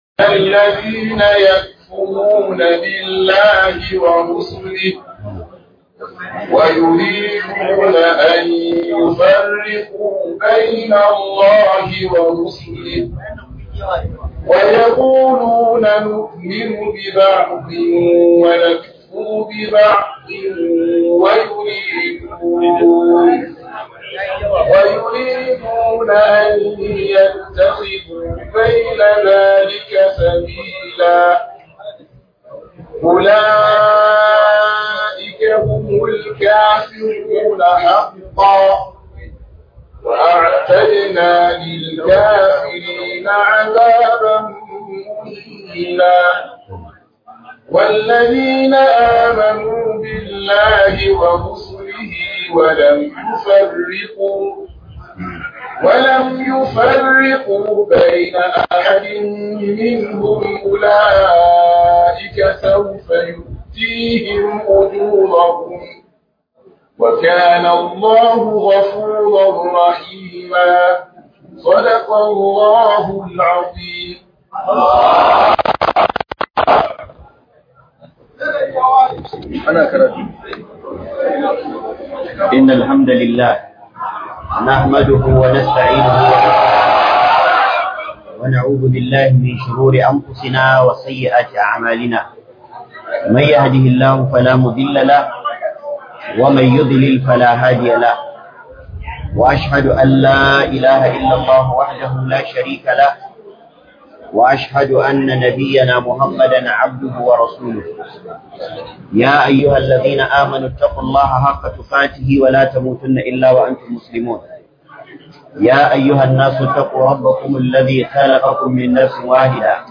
Alaqa Tsakanin Alqur'ani Da Hadisi - MUHADARA